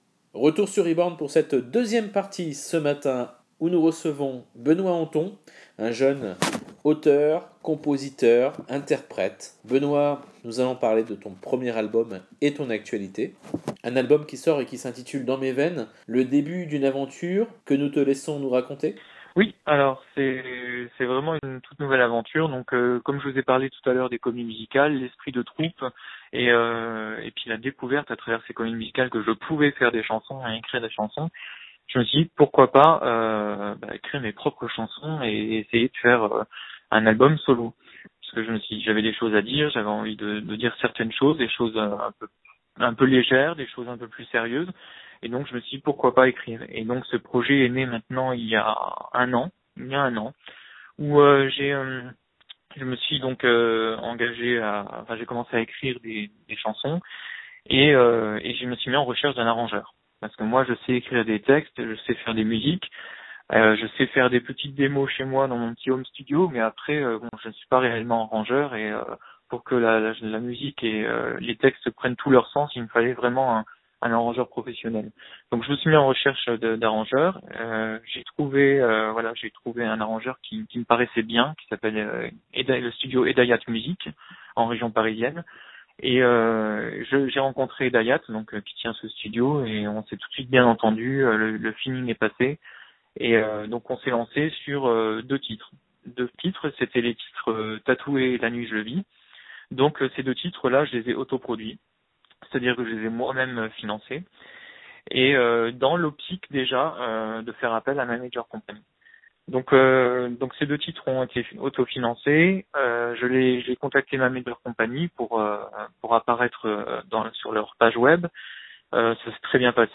Interview « Reborn Radio » – 4 octobre 2015